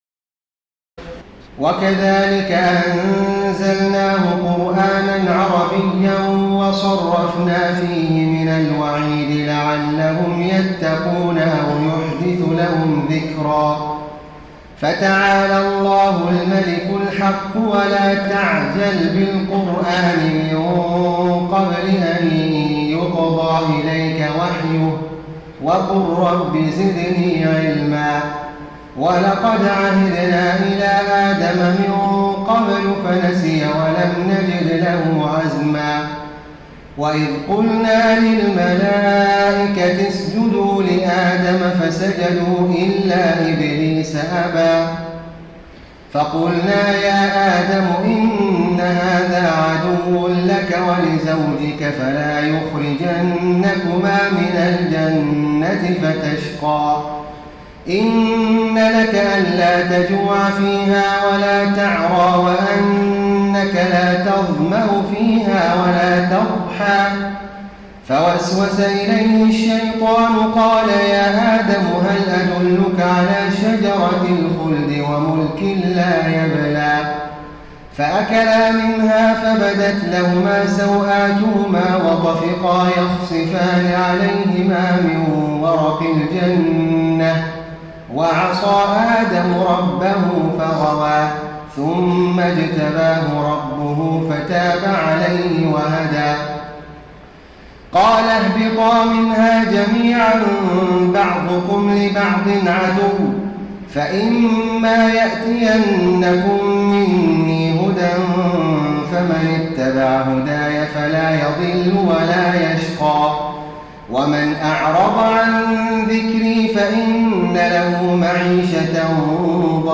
تراويح الليلة السادسة عشر رمضان 1433هـ من سورتي طه (113-135) و الأنبياء كاملة Taraweeh 16 st night Ramadan 1433H from Surah Taa-Haa and Al-Anbiyaa > تراويح الحرم النبوي عام 1433 🕌 > التراويح - تلاوات الحرمين